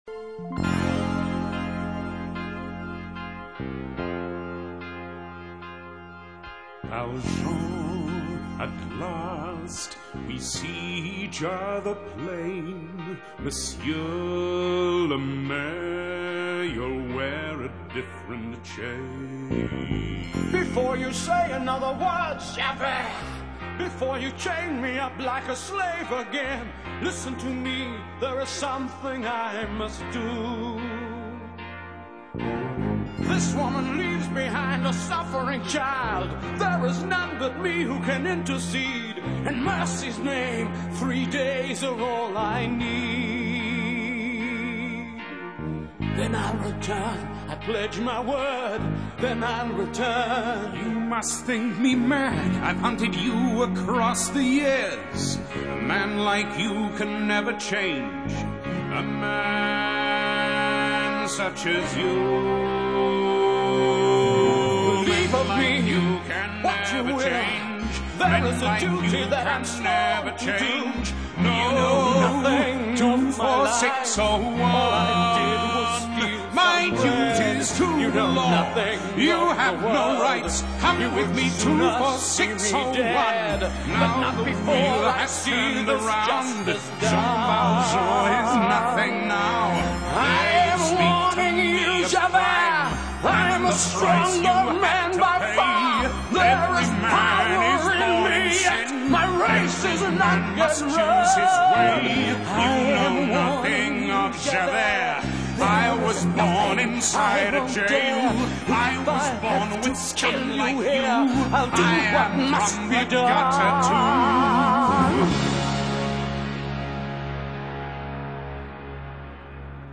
倫敦版的 Valjean 聽來較委屈求全，但在